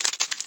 PixelPerfectionCE/assets/minecraft/sounds/mob/spider/step1.ogg at mc116